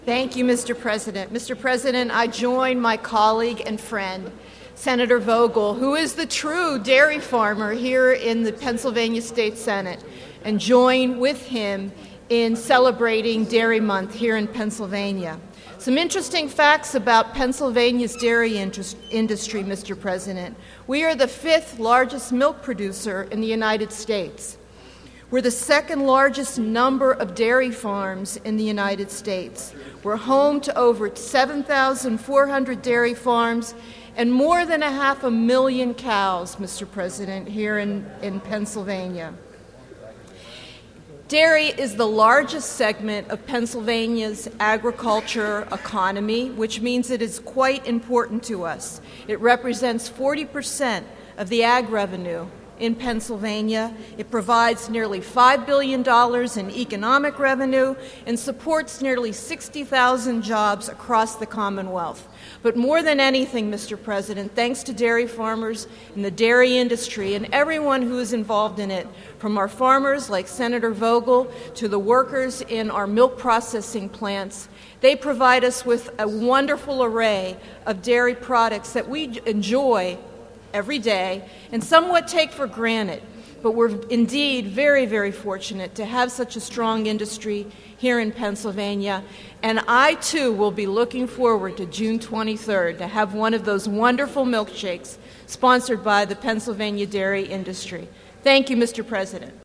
Senator Schwank, the Democratic chairman of the Senate Agriculture Committee, spoke on the floor of the Senate in support of a Resolution declaring June Dairy Month in Pennsylvania.